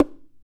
CHARANGNOIAU.wav